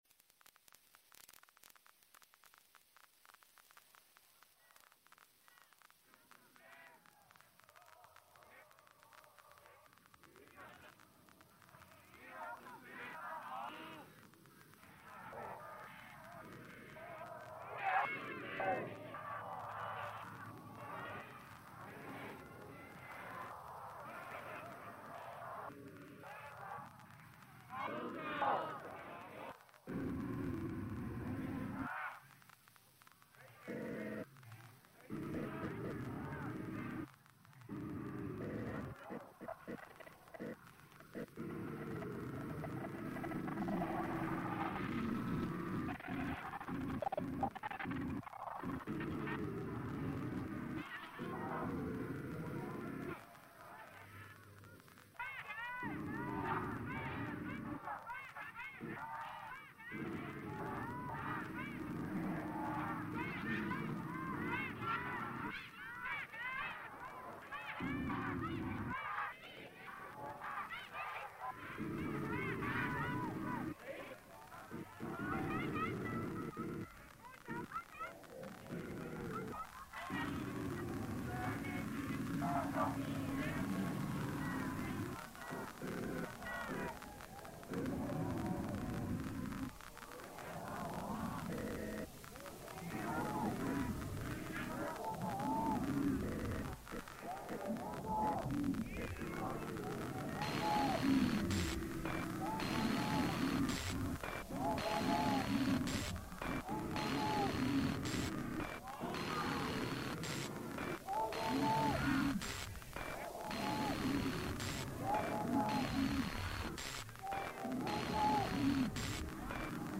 Hong Kong protest reimagined